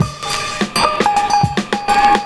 106PERCS06.wav